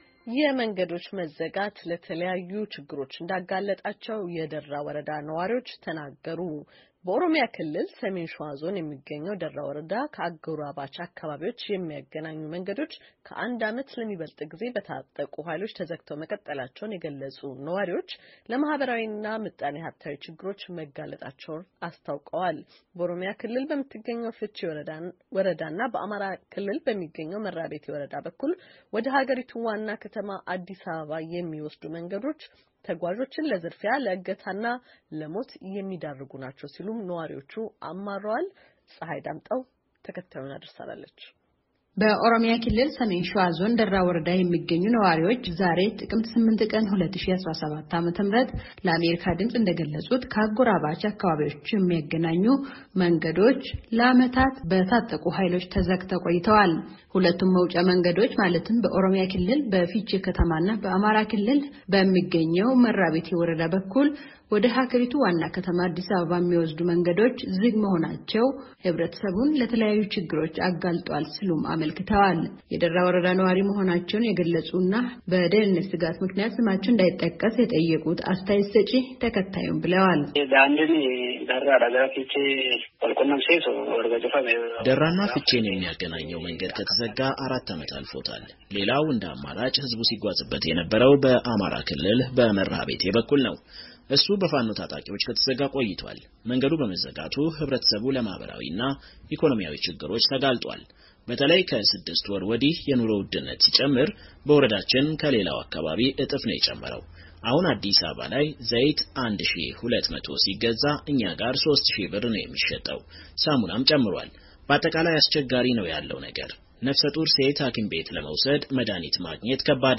ዋና አስተዳዳሪው ዛሬ ጥቅምት 8 ቀን 2017 ዓ.ም. ከአሜሪካ ድምፅ ጋራ ባደረጉት የስልክ ቃለ ምልልስ፣ የኦሮሞ ነጻነት ሠራዊት ታጣቂዎች በዞኑ አምሰት ወረዳዎች ፣ የፋኖ ታጣቂዎች ደግሞ በሦስት ወረዳዎች ውሰጥ እንደሚንቀሳቀሱ ጠቅሰው ለመንገዶቹ መዘጋትና በአካባቢው ላለው የጸጥታ ችግርም ሁለቱን ቡድኖች ተጠያቂ አድርገዋል።